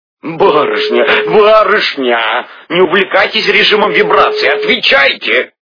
» Звуки » Люди фразы » Михаил Галустян - барышня, не увлекайся режимом вибрации, отвечайте
При прослушивании Михаил Галустян - барышня, не увлекайся режимом вибрации, отвечайте качество понижено и присутствуют гудки.